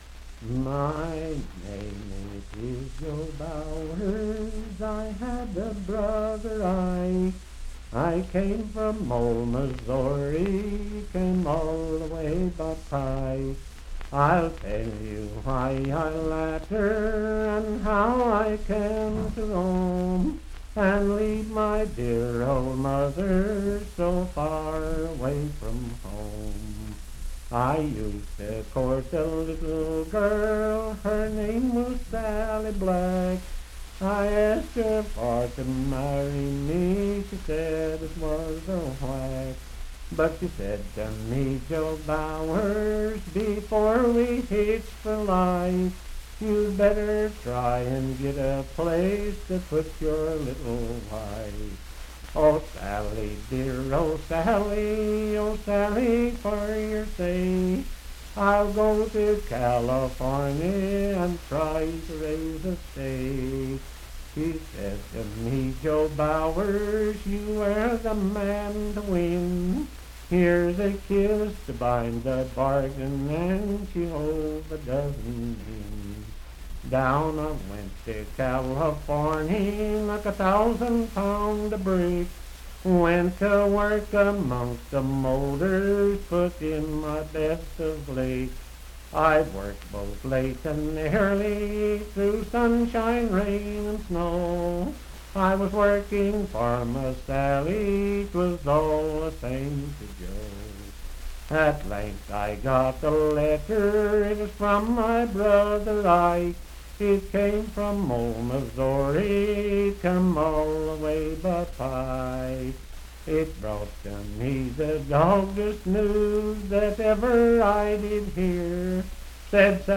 Unaccompanied vocal music
in Dryfork, WV.
Voice (sung)
Randolph County (W. Va.)